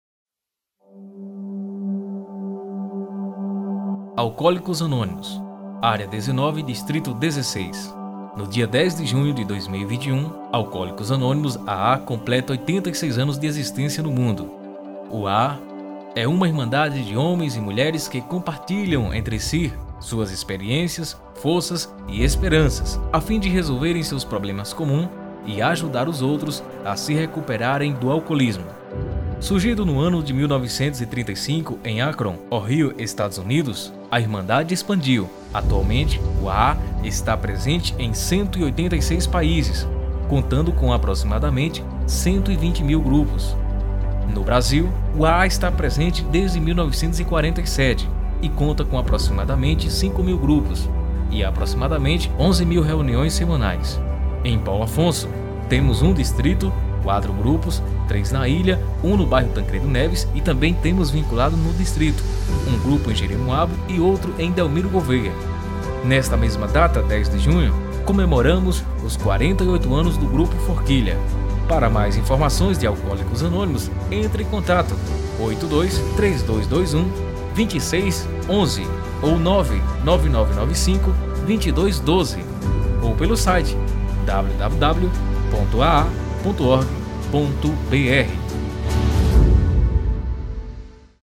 SPOT-AA.mp3